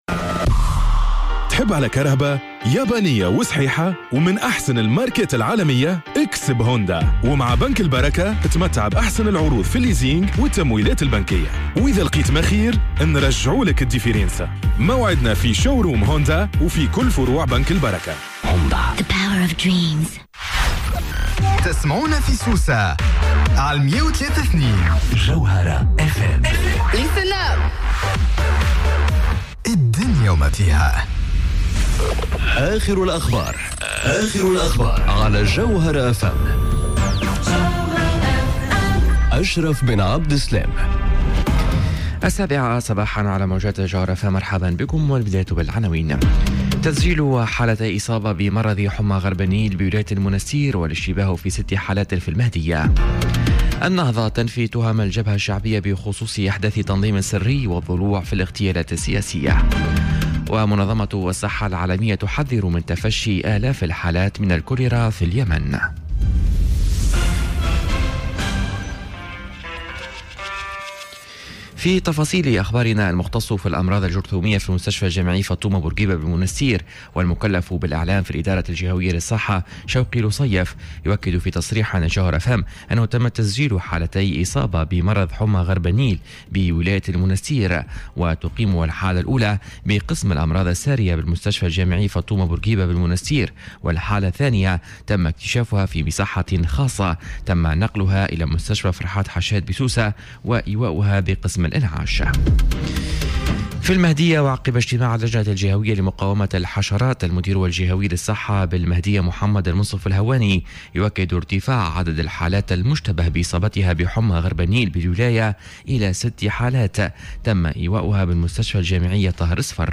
نشرة أخبار السابعة صباحا ليوم الإربعاء 03 أكتوبر 2018